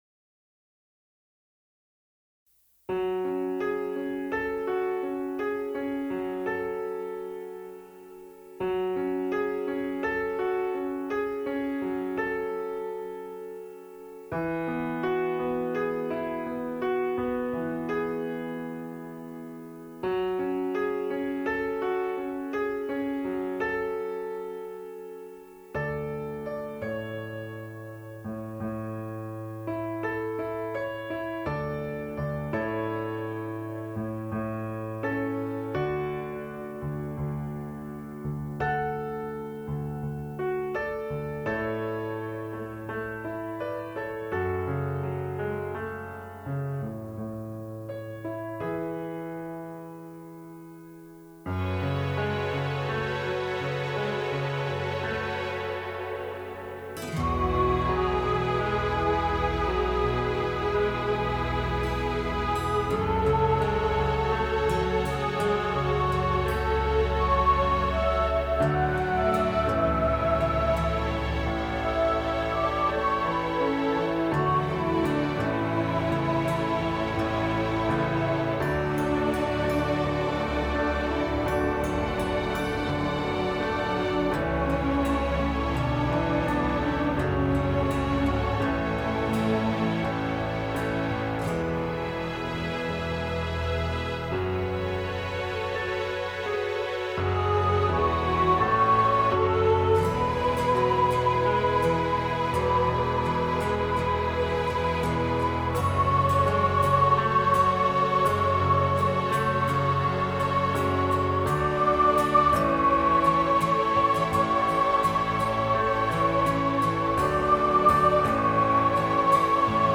Sound Of Silence – Soprano | Ipswich Hospital Community Choir